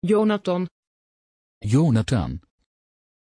Pronunciación de Joonatan
pronunciation-joonatan-nl.mp3